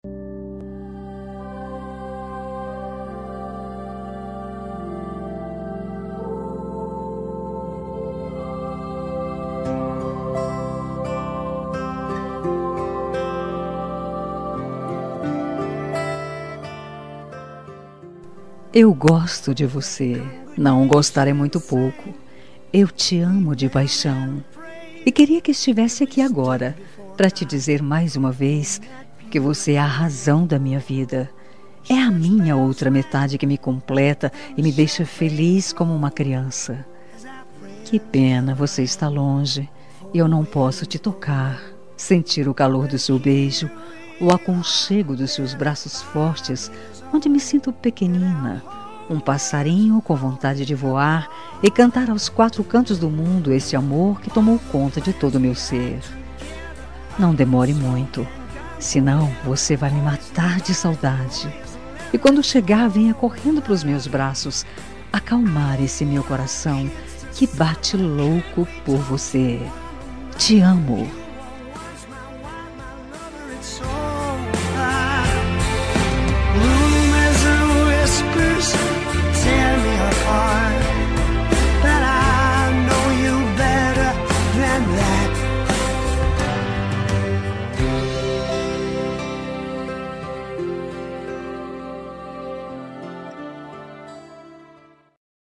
Telemensagem de Saudades – Voz Feminina – Cód: 442